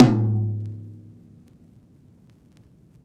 CYCdh_VinylK4-Tom02.wav